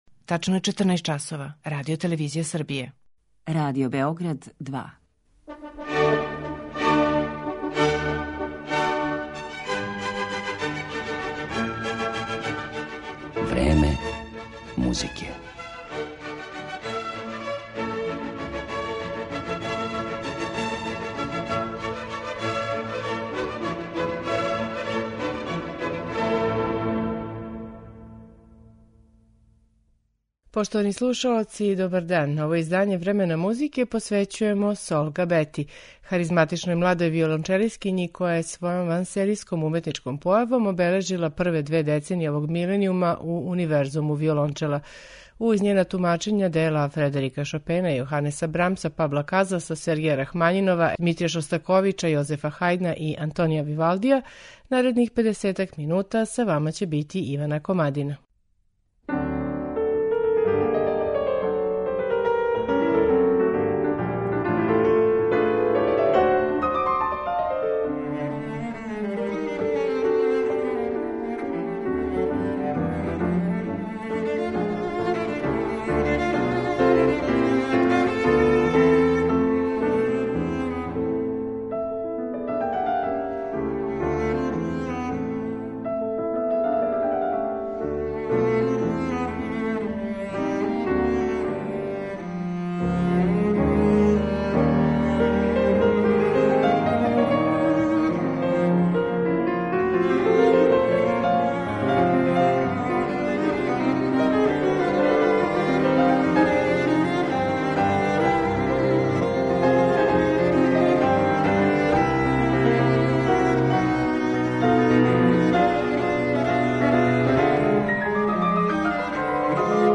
Сол Габета, виолончело